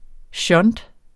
Udtale [ ˈɕʌnt ]